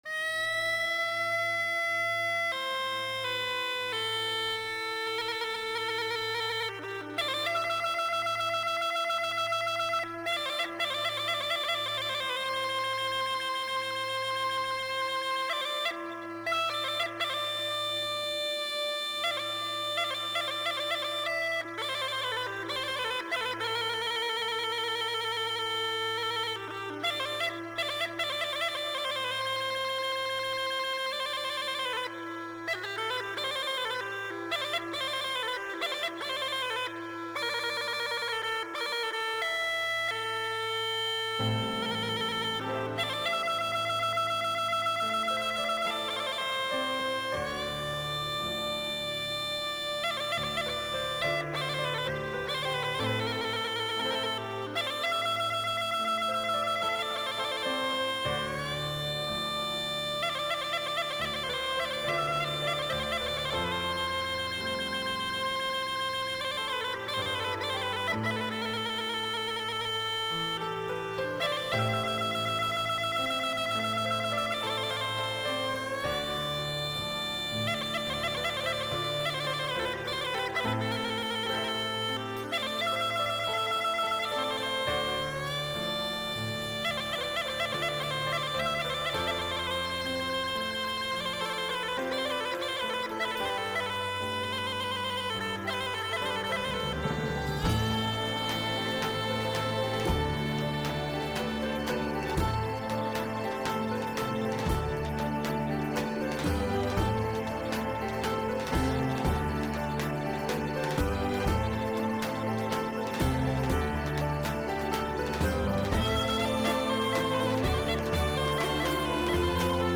Μιλήσαμε στην εκπομπή για το έργο του Georgi Gospodinov